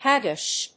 音節hág・gish 発音記号・読み方
/‐gɪʃ(米国英語)/